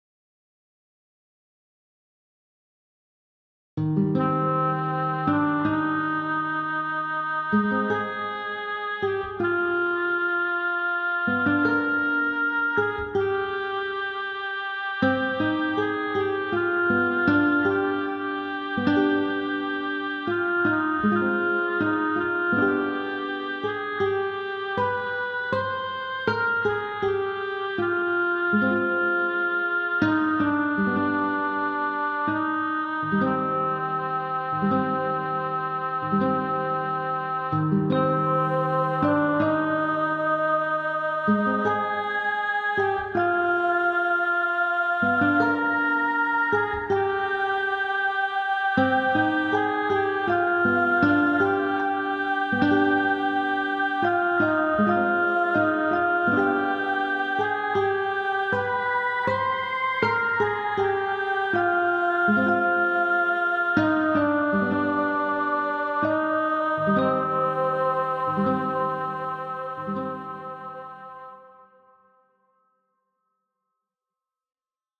-Ambient
The link above are in low quality, if you want a better sound, you must download the FLAC ones from OGA.